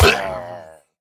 Minecraft Version Minecraft Version snapshot Latest Release | Latest Snapshot snapshot / assets / minecraft / sounds / mob / pillager / death2.ogg Compare With Compare With Latest Release | Latest Snapshot
death2.ogg